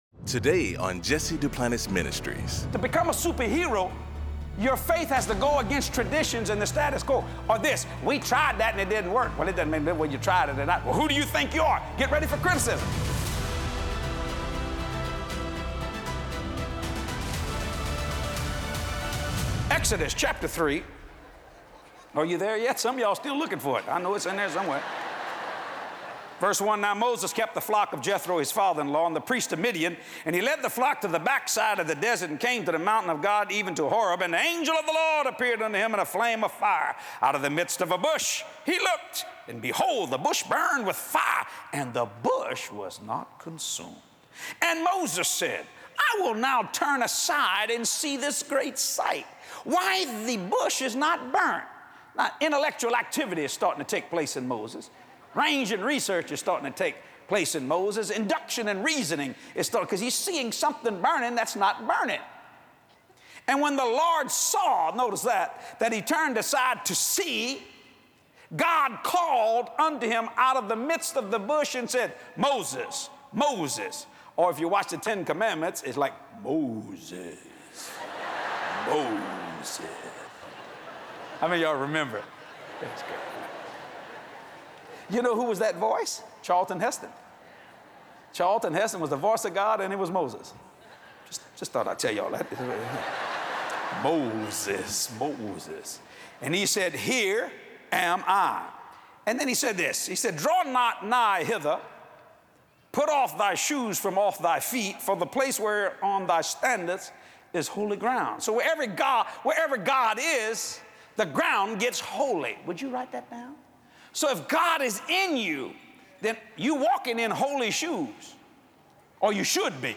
In this classic message